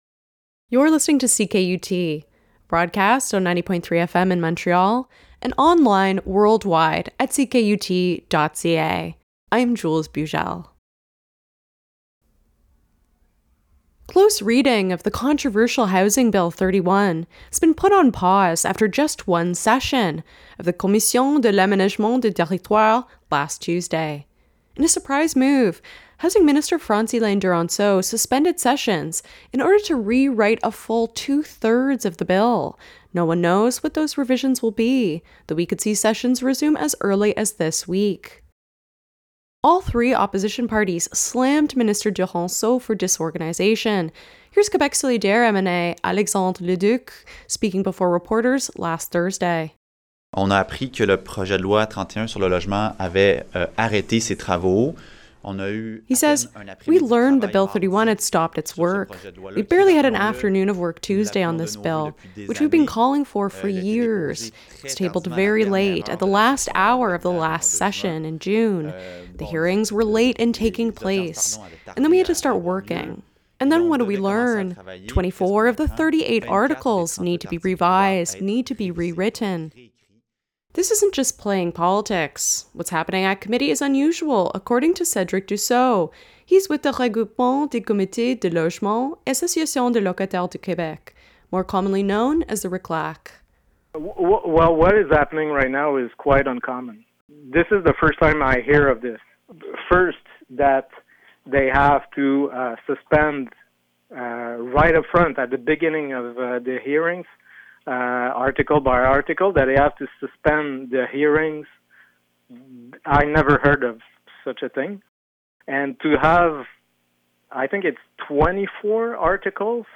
Quebec Solidaire's Alexandre Leduc criticized the Housing Minister's "disorganization" on this bill in front of reporters on Thursday.